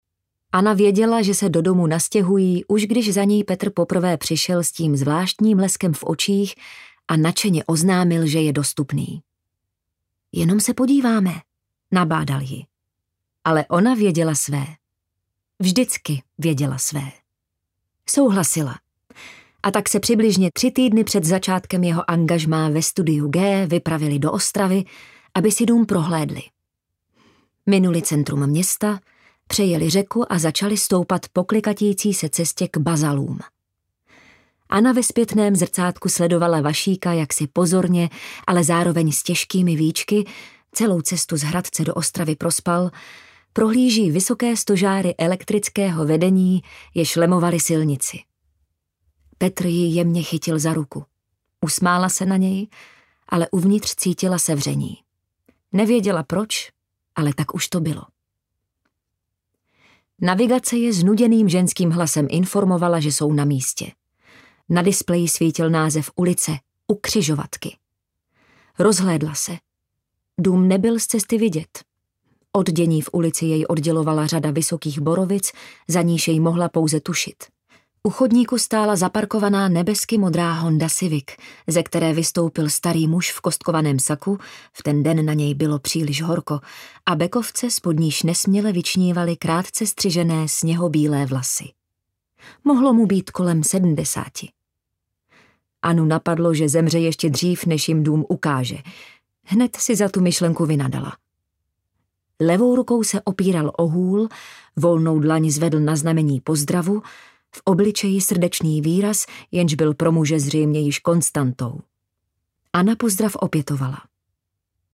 Papírové domky audiokniha
Ukázka z knihy